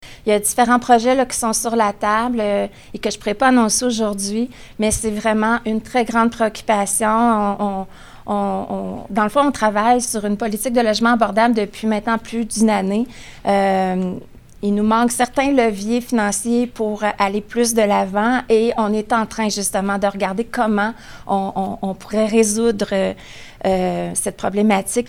Comme l’a mentionné la mairesse de Bécancour, Lucie Allard, en conférence de presse mardi, le défi sera désormais de voir pousser des habitations avec des loyers abordables.